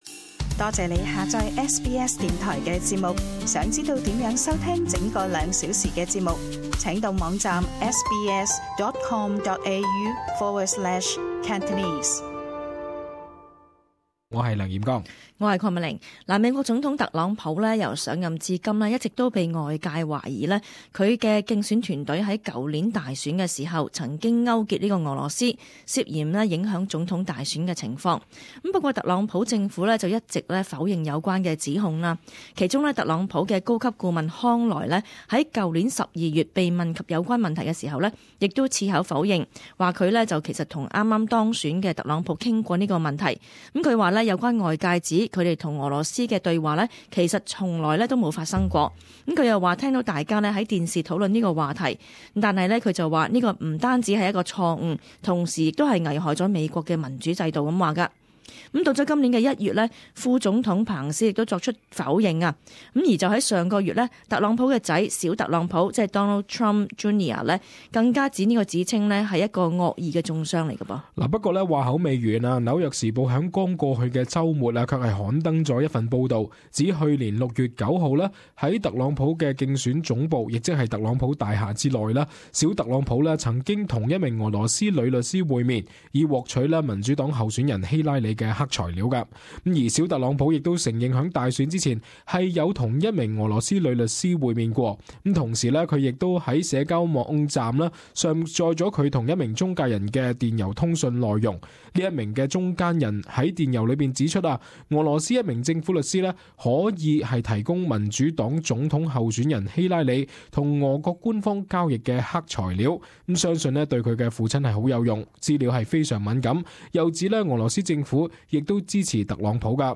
【時事報導】小特朗普涉嫌捲入勾結俄羅斯醜聞